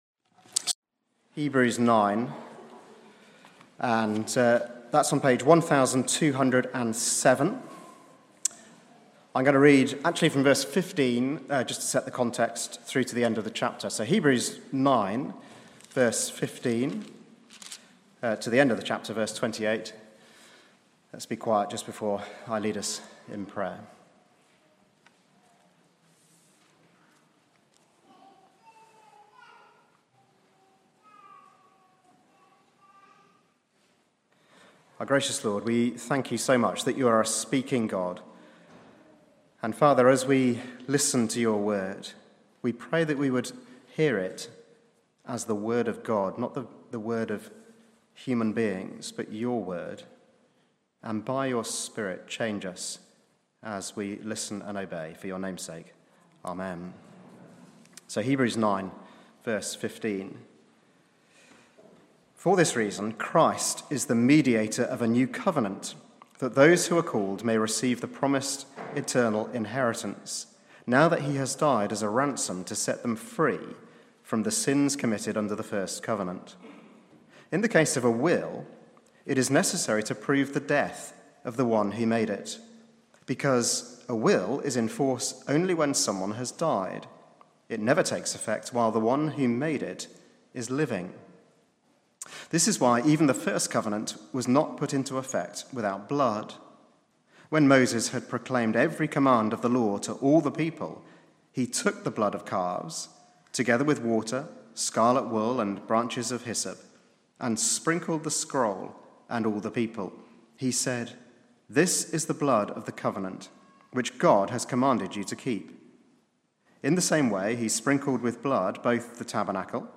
Like this sermon?